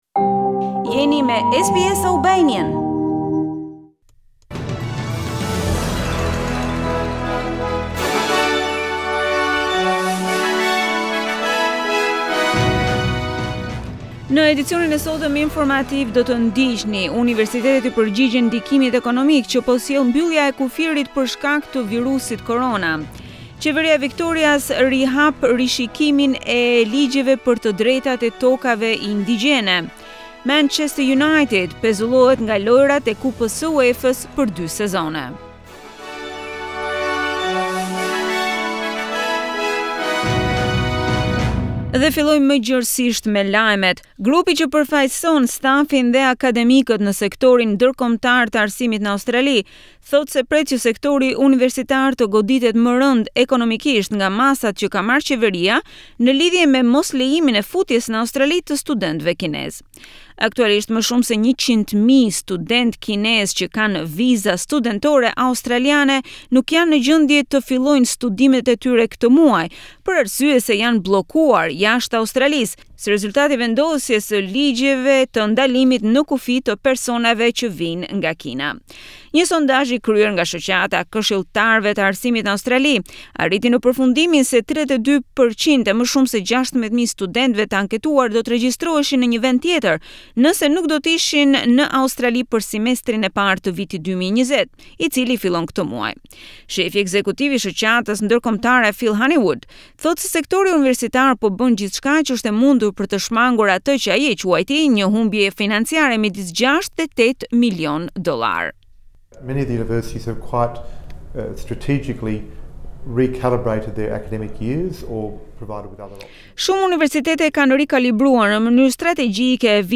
SBS News Bulletin 15 February 2020